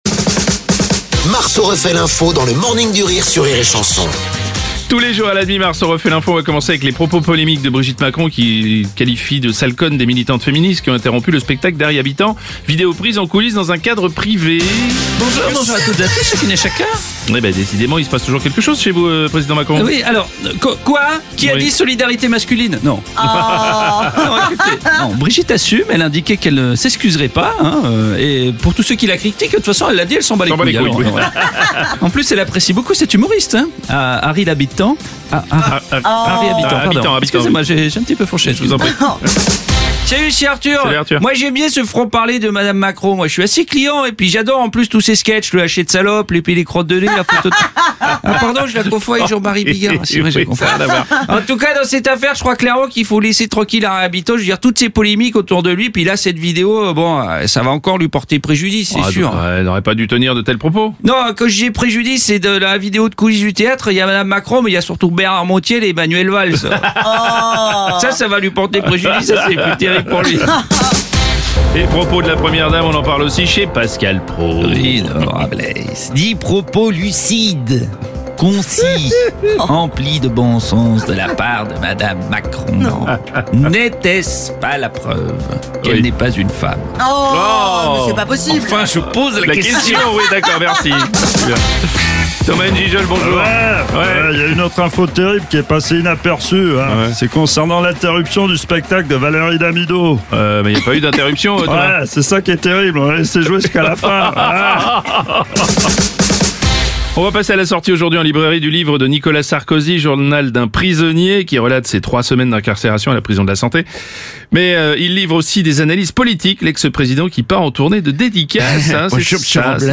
L’imitateur
en direct à 7h30, 8h30, et 9h30.